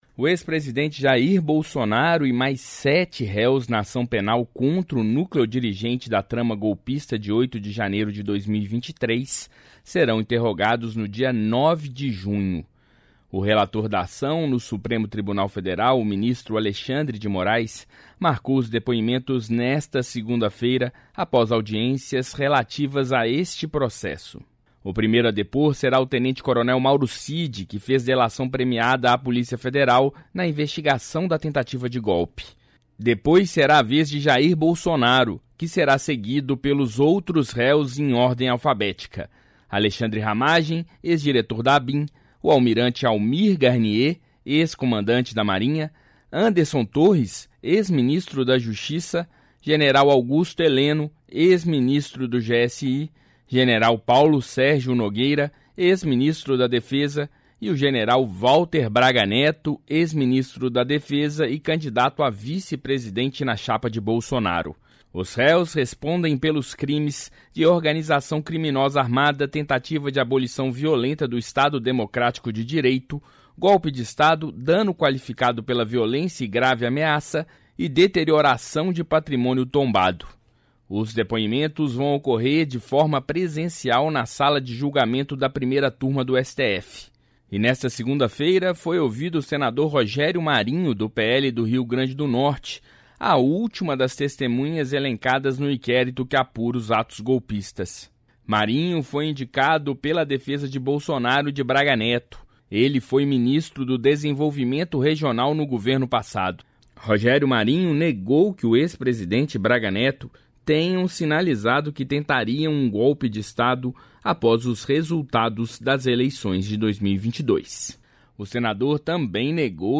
Rádio Agência